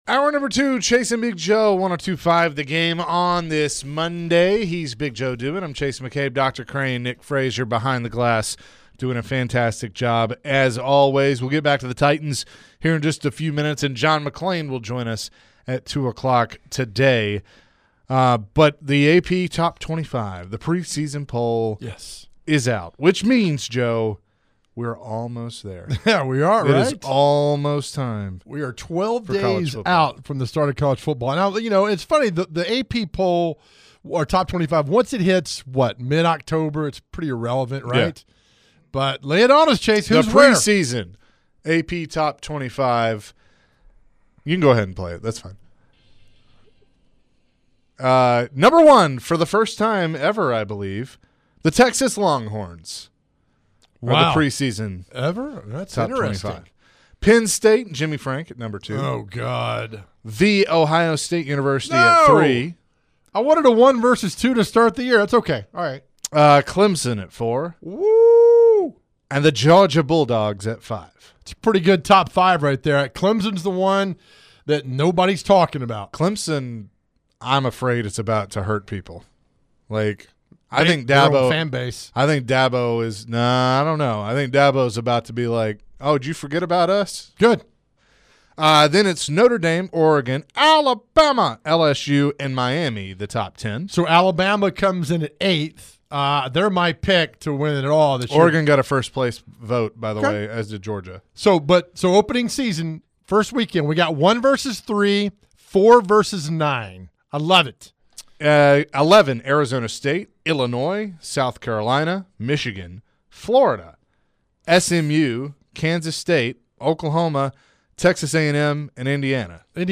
Could Jon Gruden return to coaching, but in the SEC? To end the hour, the guys answered some phones and posed the, what if scenario if Josh Heupel went home to Oklahoma.